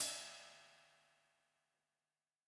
KIN Ride.wav